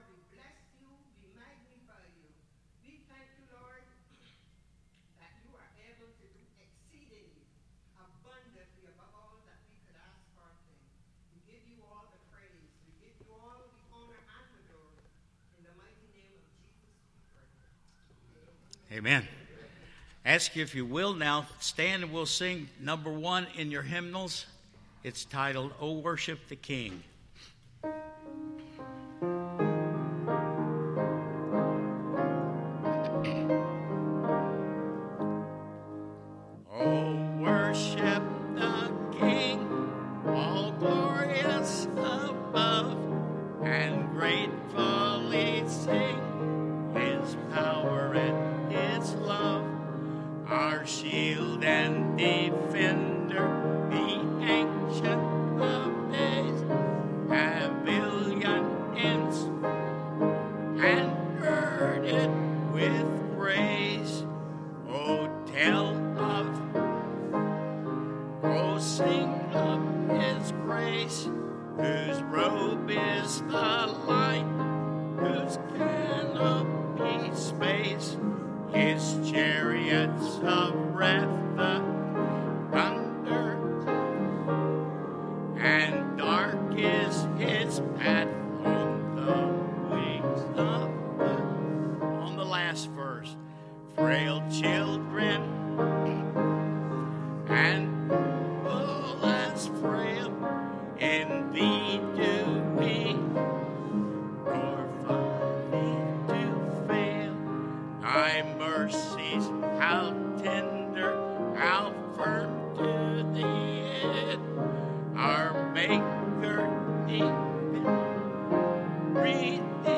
Sunday Morning Service – November 29, 2020